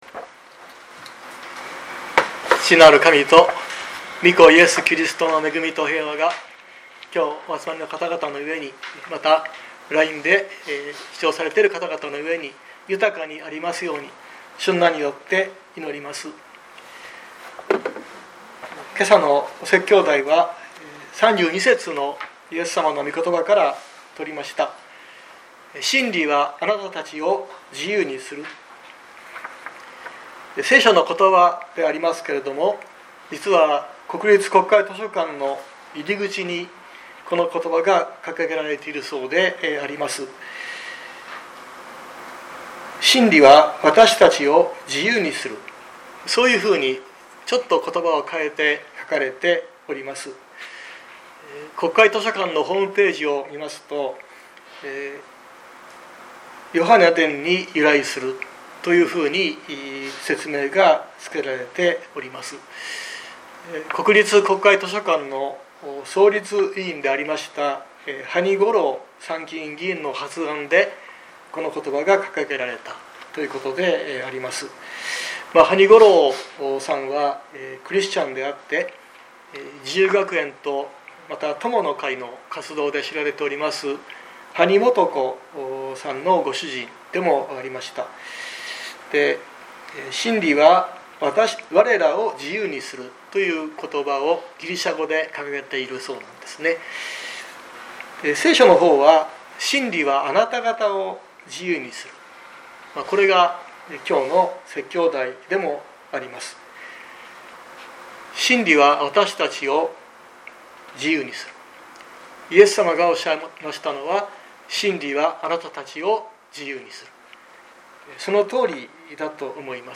熊本教会。説教アーカイブ。
日曜朝の礼拝